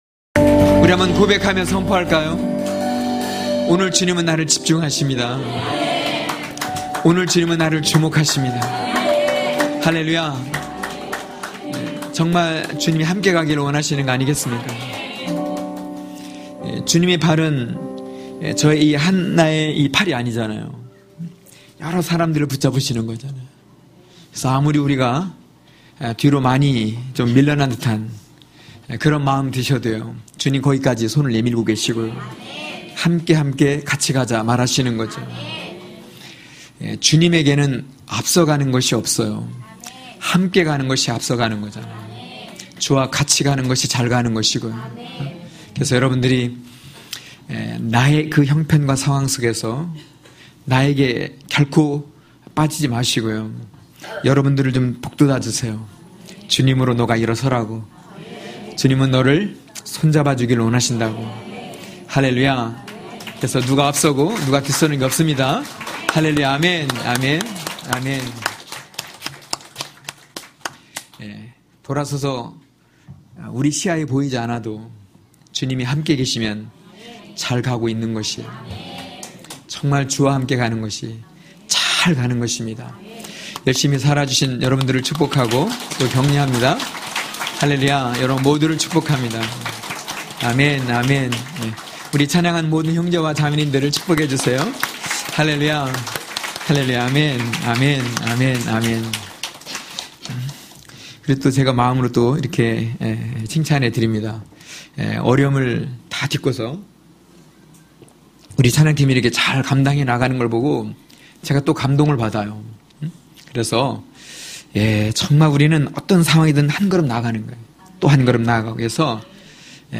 강해설교 - 10.어제를 가르고 오늘로..(느5장11~19절).mp3